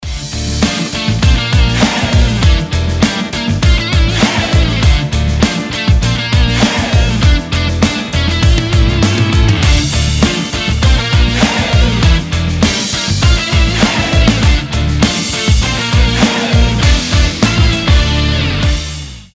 • Качество: 320, Stereo
гитара
бодрые
Бодрящий проигрыш с электрогитарой